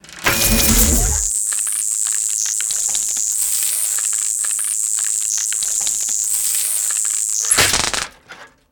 laser.ogg